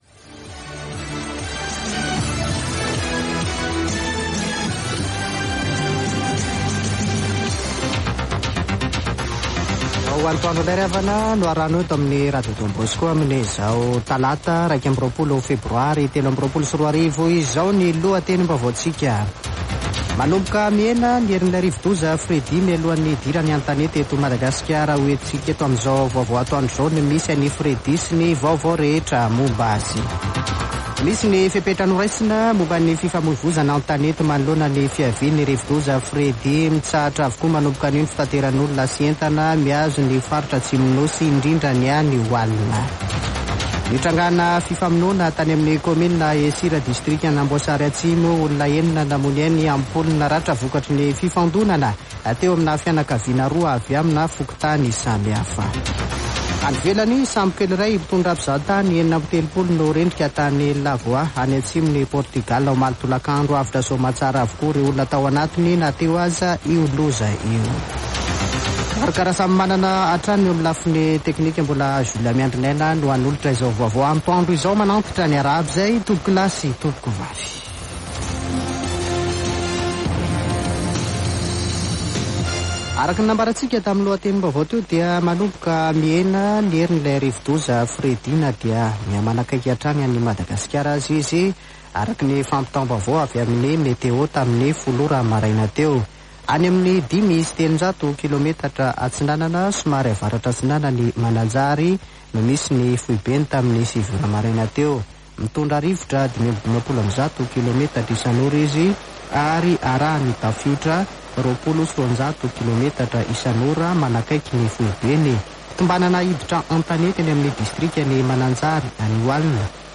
[Vaovao antoandro] Talata 21 febroary 2023